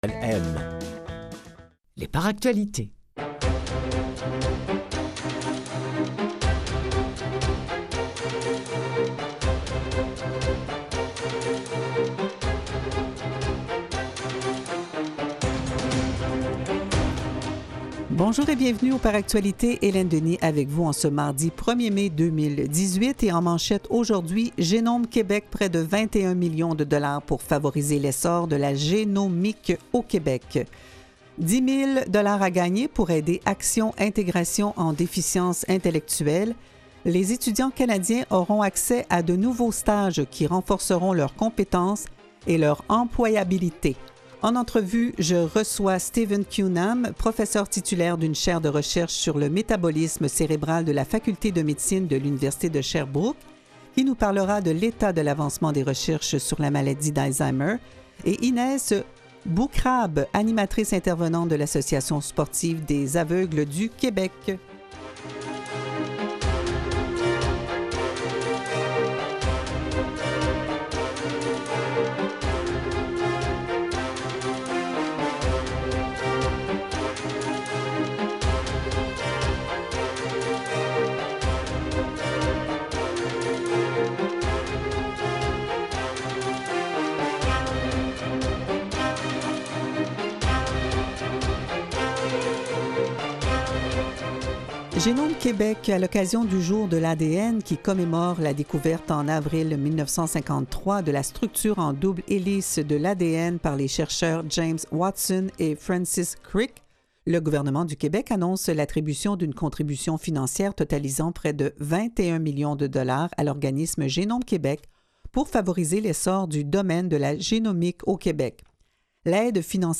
LES ENTREVUES DU JOUR